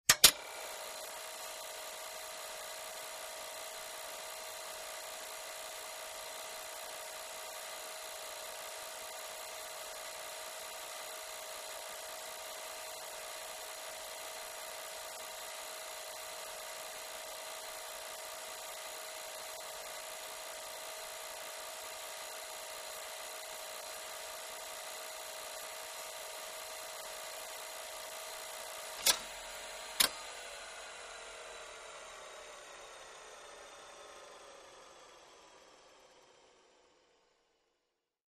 3 /4" Video tape deck tape loads and plays. Tape Loading Transport Engage